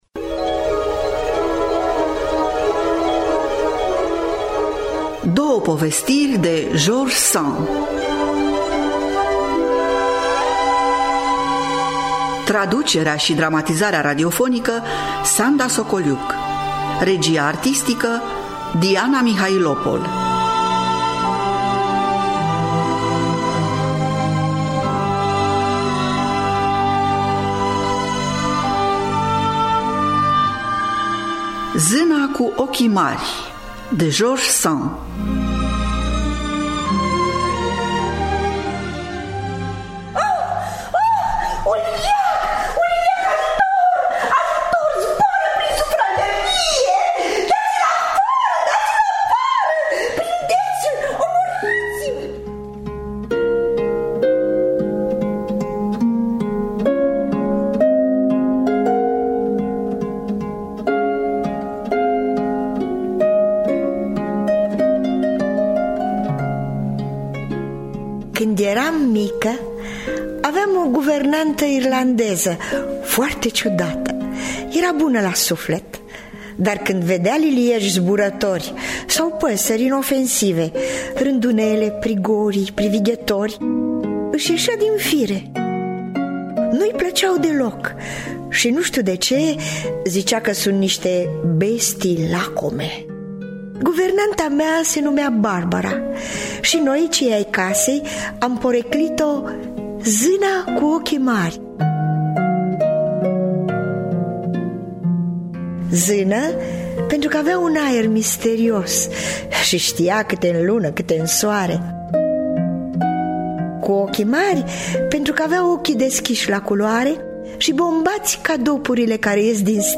“Zâna cu ochii mari” și “Zâna prafului” de George Sand – Teatru Radiofonic Online